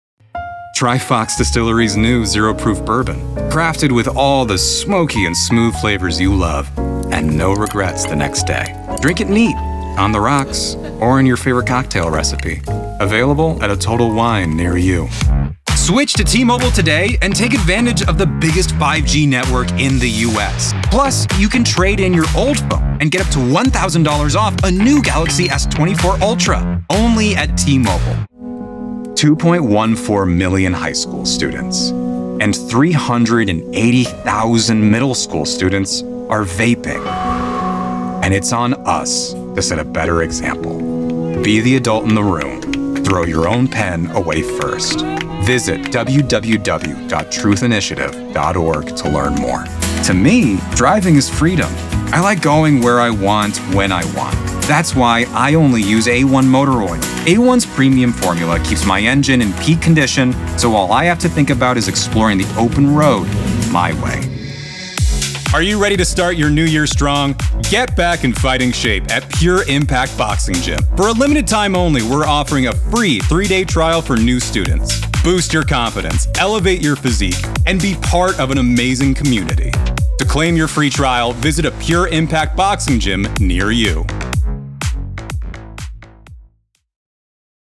Voiceover Demos
Recorded in my home studio in Tucson, AZ with:
Microphone- Shure SM7B
Commercial
Whether you want to seize attention with silliness and novelty or you need a sincere, emotional approach, I can adapt.